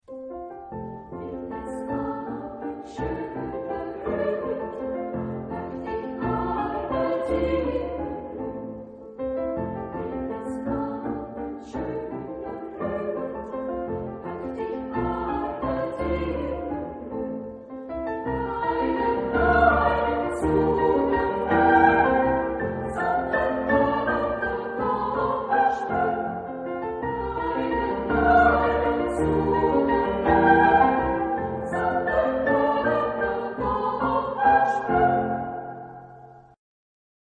Genre-Style-Forme : Profane ; Romantique ; Valse ; Pièce chorale ; Cycle
Type de choeur : SA  (2 voix égales de femmes )
Instrumentation : Piano  (2 partie(s) instrumentale(s))
Instruments : Piano à 4 mains (1)
Tonalité : fa majeur